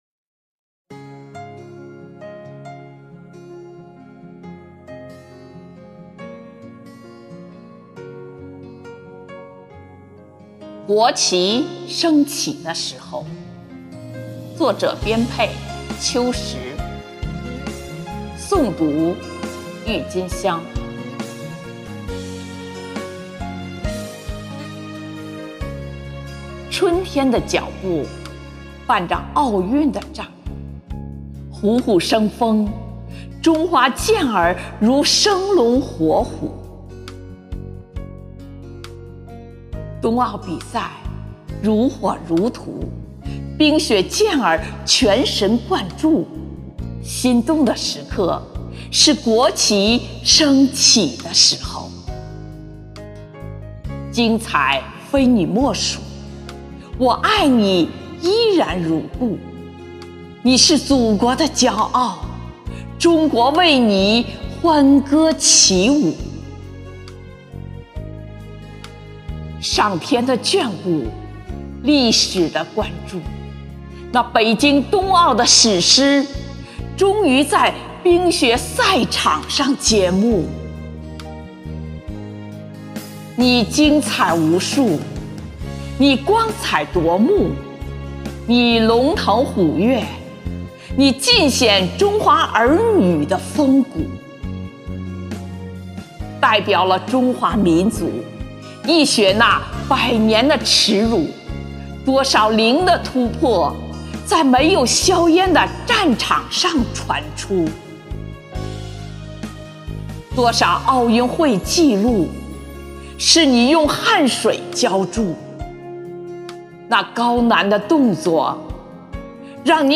【生活好课堂】冬奥主题朗诵集锦（八里庄西里朗读支队）